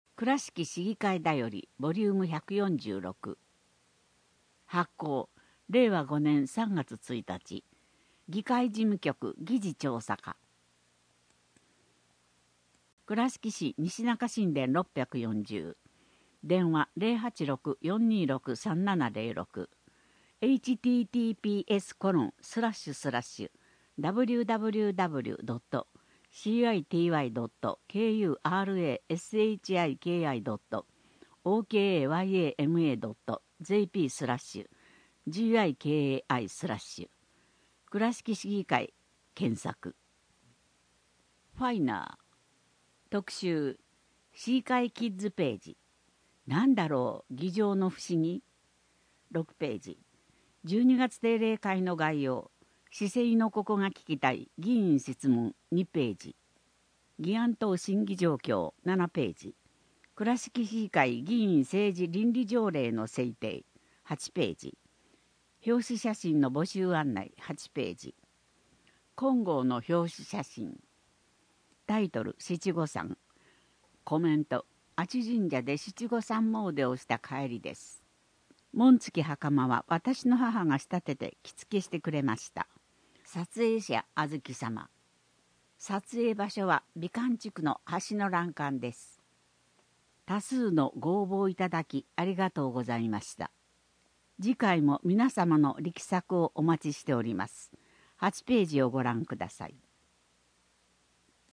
4. 令和４年度　声の議会だより